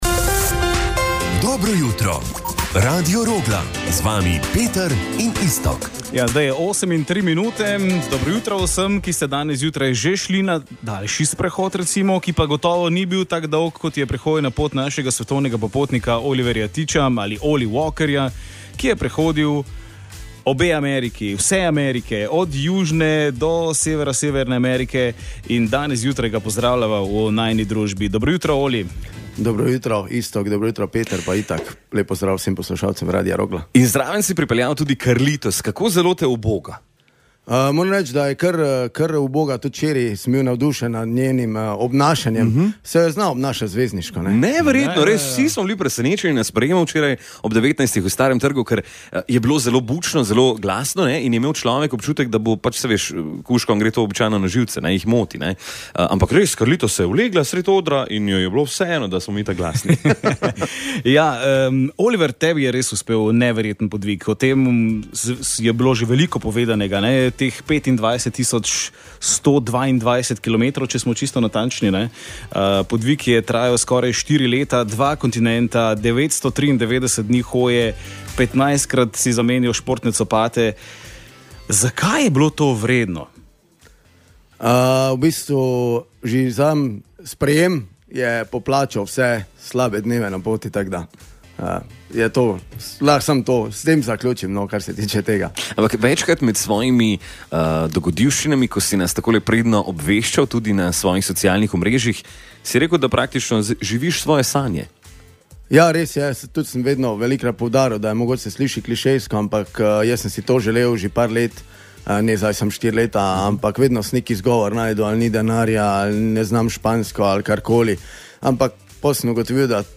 Z neverjetnima popotnikoma smo preživeli ponedeljkovo jutro na Radiu Rogla.